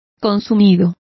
Also find out how consumidos is pronounced correctly.